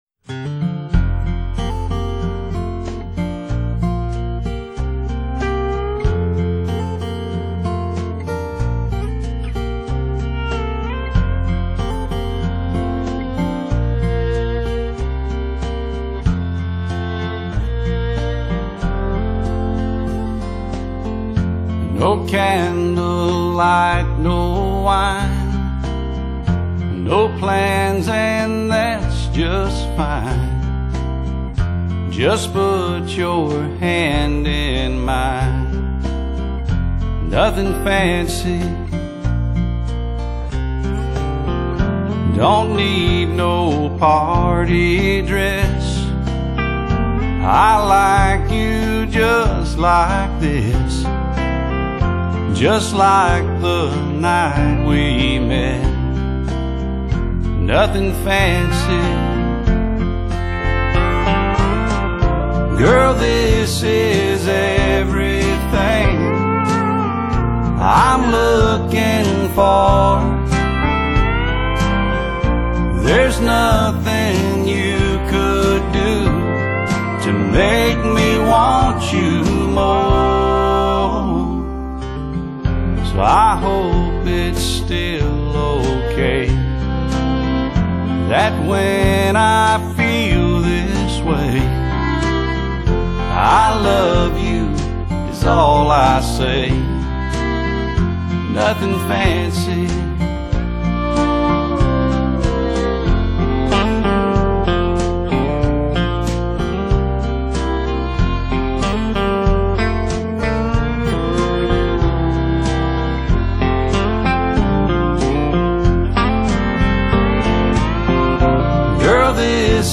最爲地道的新傳統鄉村樂，以及輕鬆愉快的風格